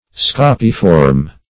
scapiform - definition of scapiform - synonyms, pronunciation, spelling from Free Dictionary Search Result for " scapiform" : The Collaborative International Dictionary of English v.0.48: Scapiform \Sca"pi*form\, a. (Bot.)